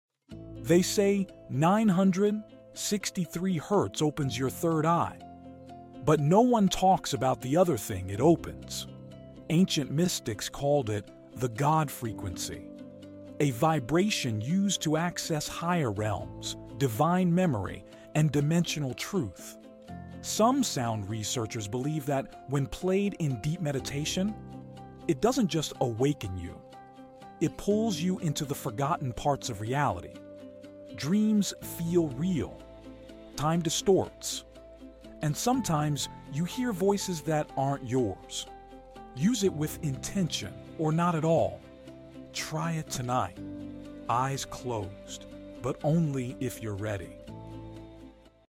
963 Hz, The God Frequency.